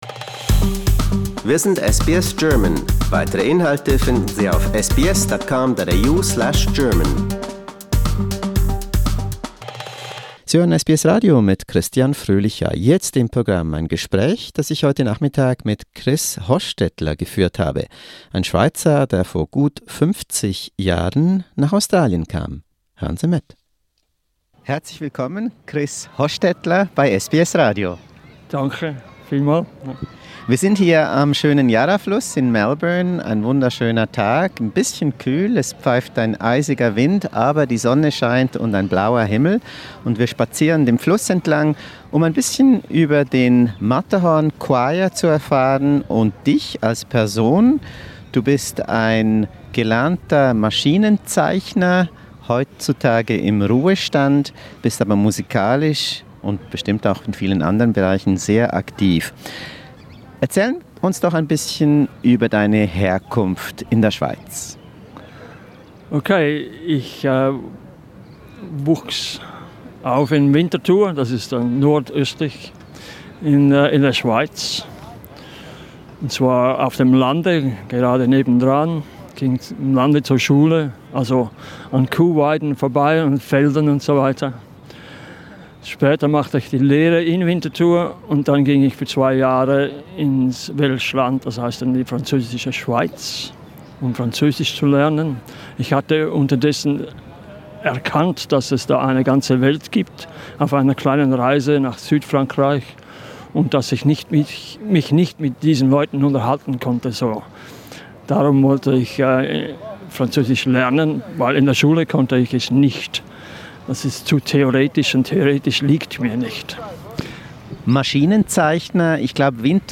In this interview, recorded during a peaceful stroll on the Yarra river banks in Melbourne, he tells us why and how it connects him to his former home.